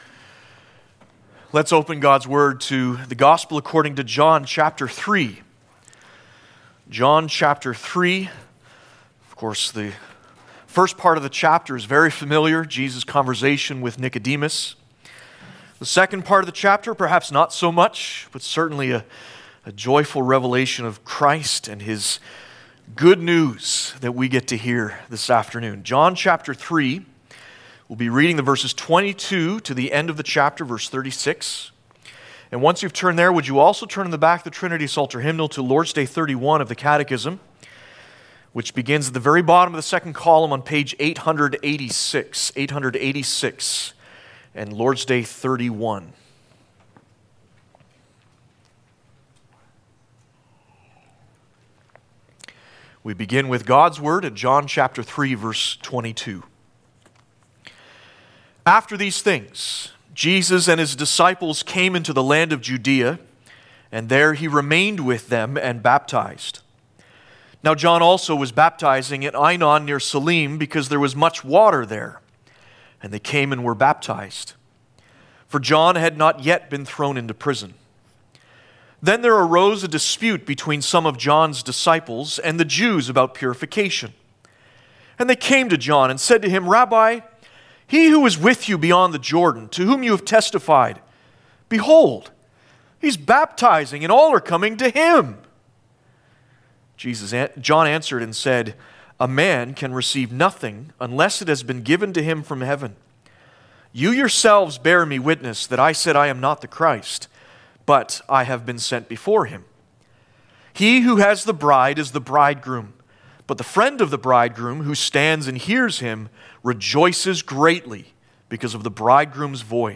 Passage: John 3:22-36 Service Type: Sunday Afternoon